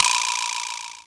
Vibrasla.wav